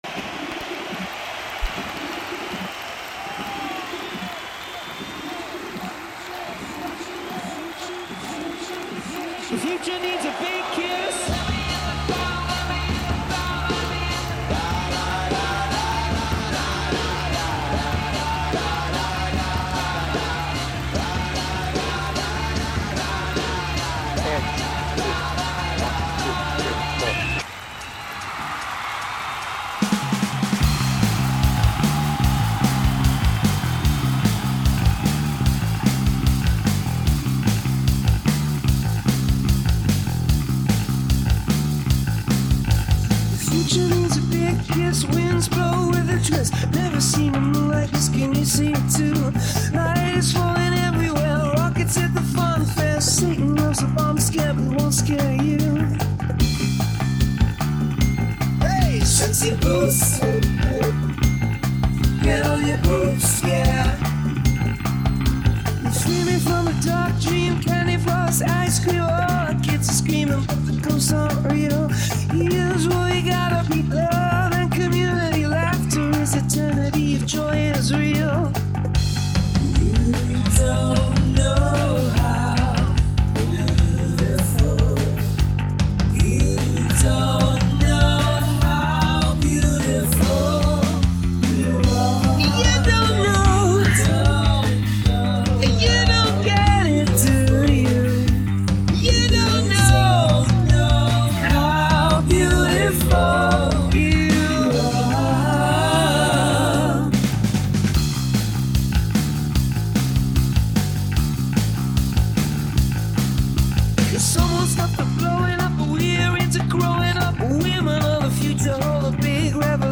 BPM : 103
Tuning : E
Without vocals
Based on the album version